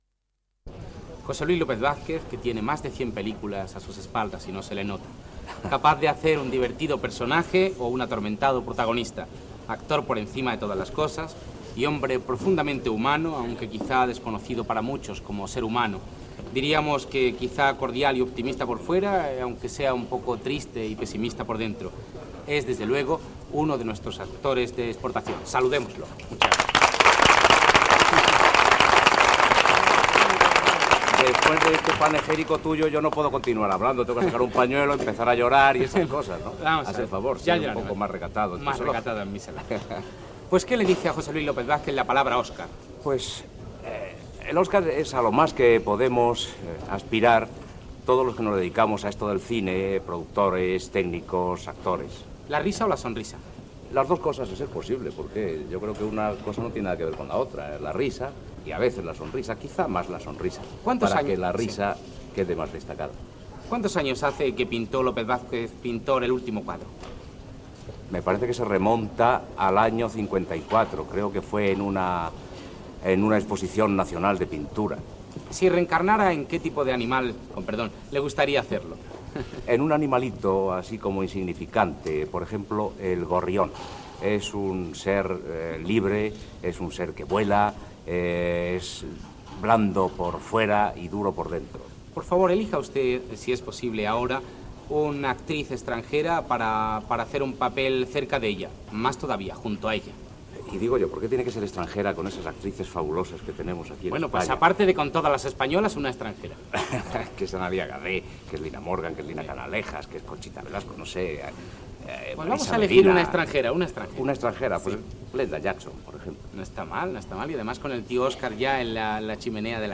Entrevista a l'actor José Luis López Vázquez feta amb preguntes breus
Entreteniment